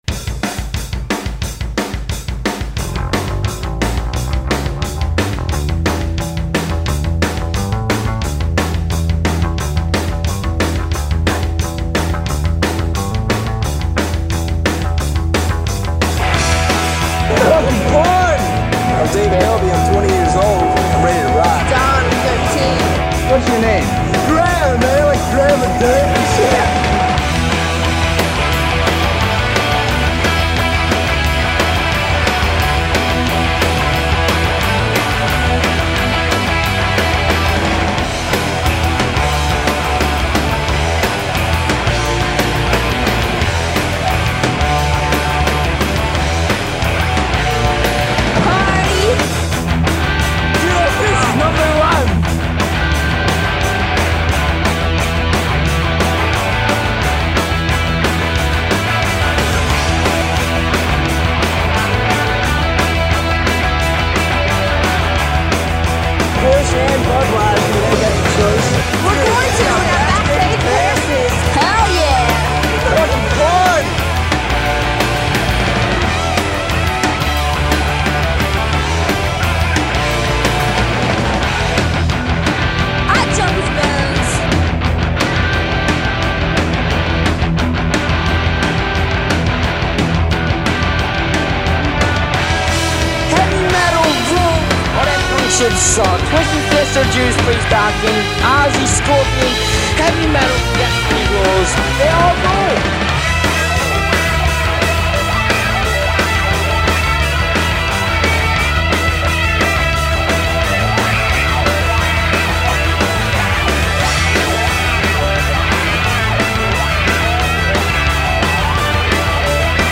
It consisted of Me on giutar vocals.
on guitar and vocals.
bass
drums. This song used sound clips from Heavy Metal Parking Lot documentary.